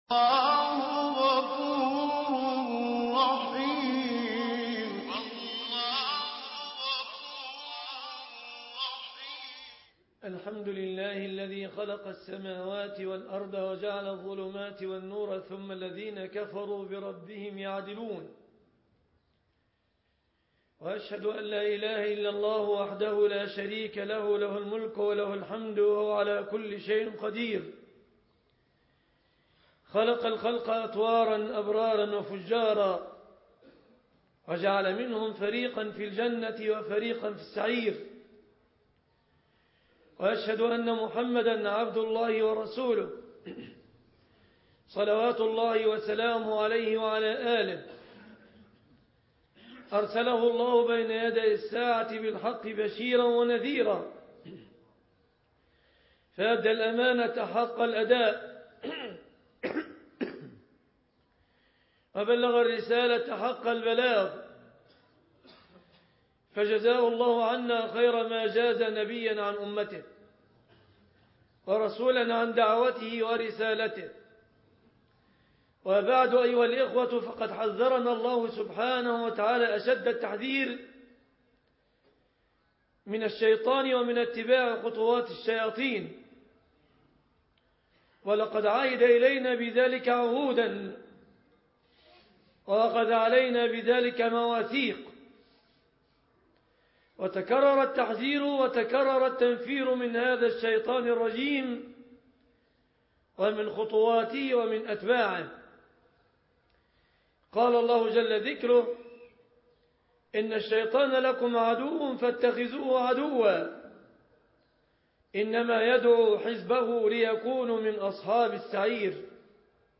المنبر